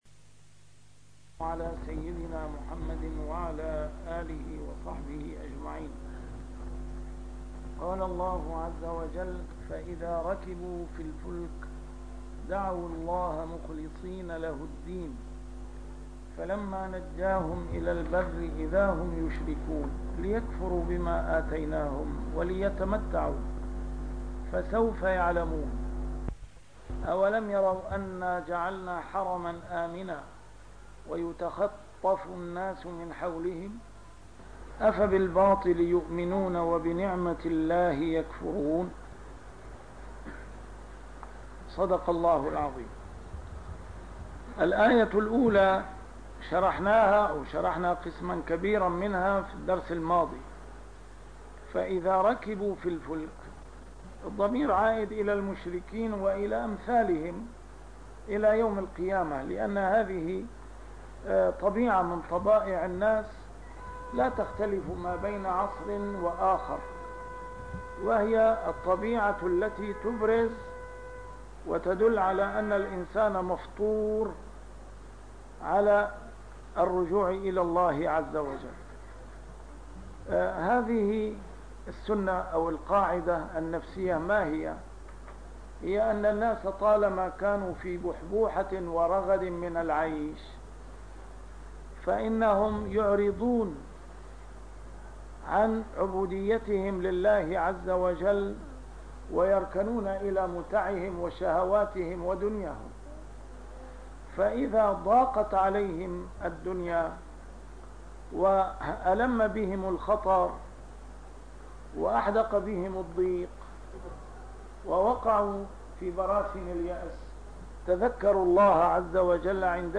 A MARTYR SCHOLAR: IMAM MUHAMMAD SAEED RAMADAN AL-BOUTI - الدروس العلمية - تفسير القرآن الكريم - تسجيل قديم - الدرس 312: العنكبوت 65-67